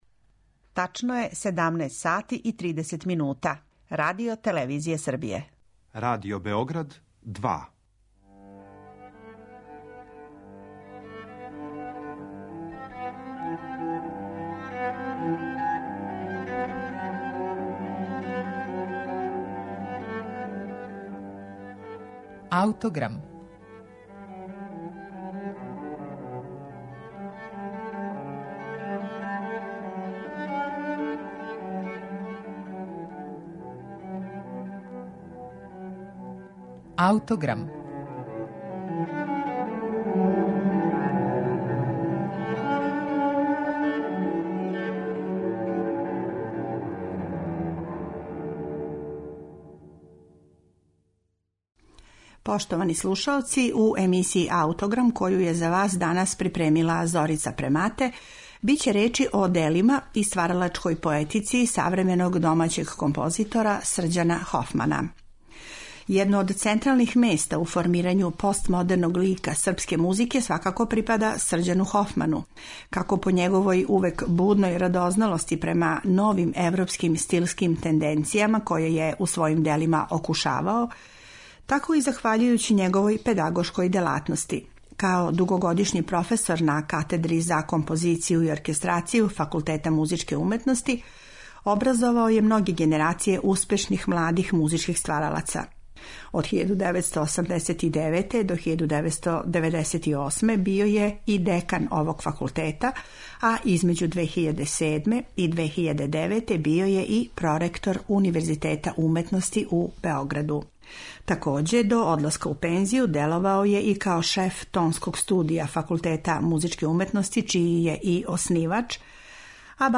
за две озвучене харфе и процесоре звука